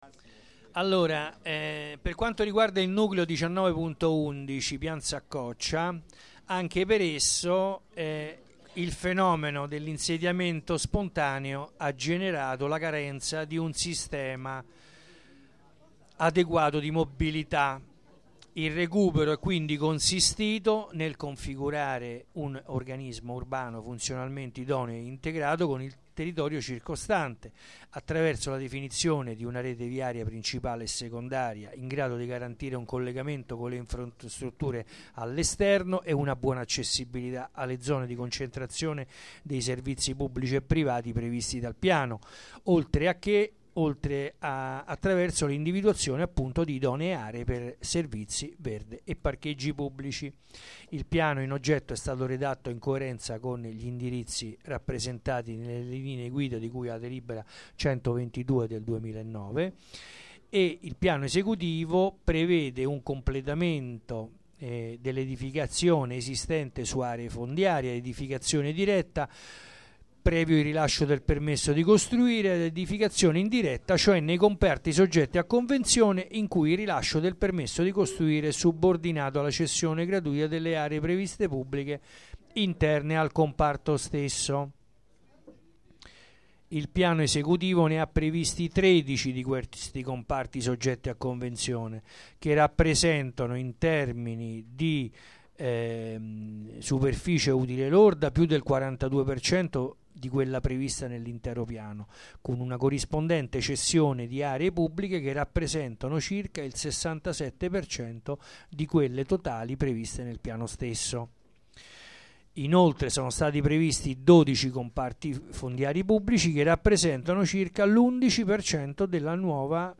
Assemblea
Registrazione integrale dell'incontro svoltosi il 3 aprile 2013 presso la sala consiliare del Municipio Roma XIX